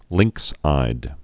(lĭngksīd)